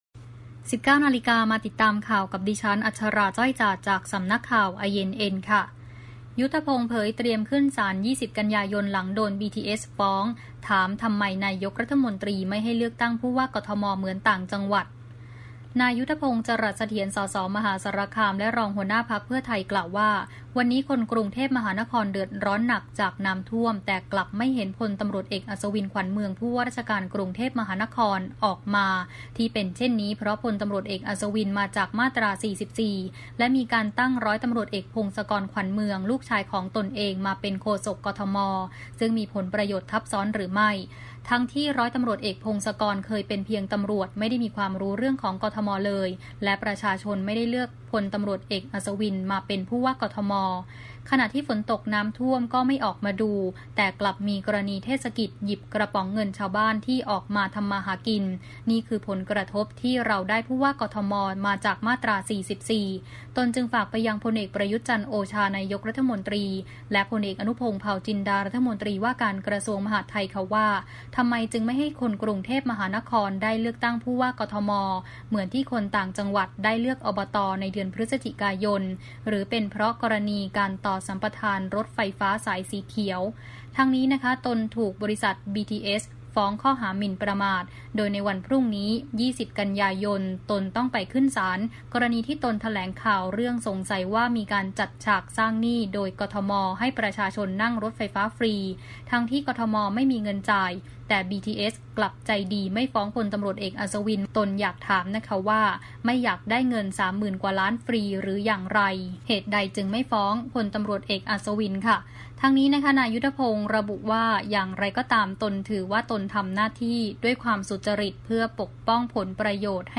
Video คลิปข่าวต้นชั่วโมง